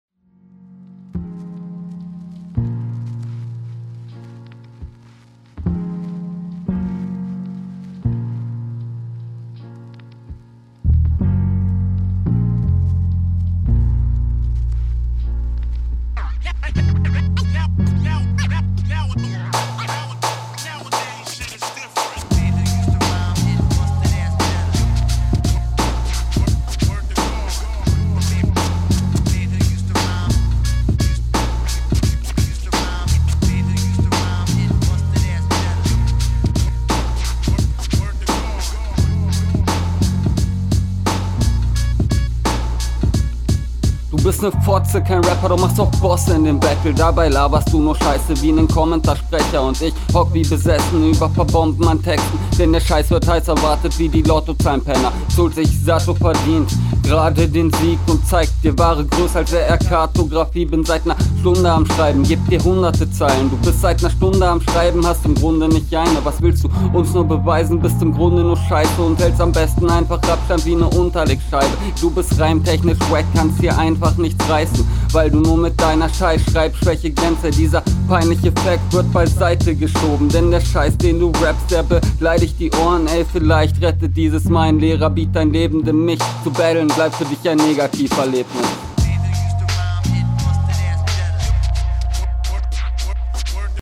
Das klingt deutlich unsicherer als in deiner anderen Runde. Hier ist die Pausensetzung auch schwächer.